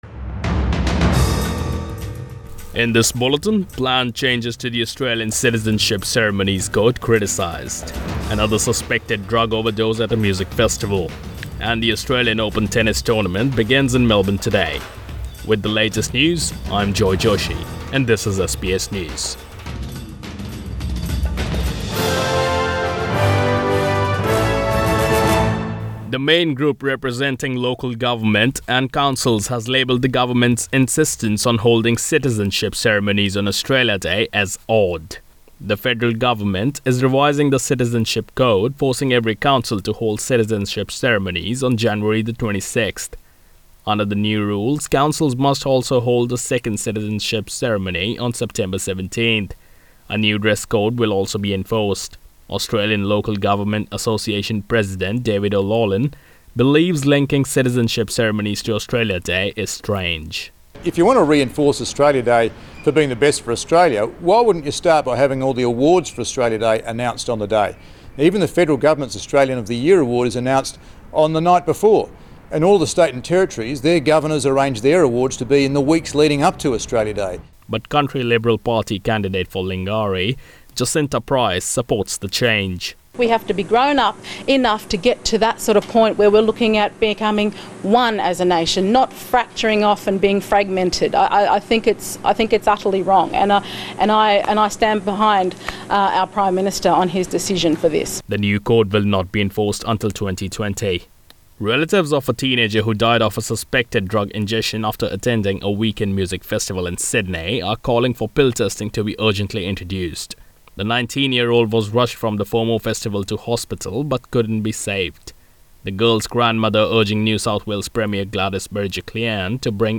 AM Bulletin 14 January